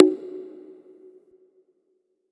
Bongo MadFlavor.wav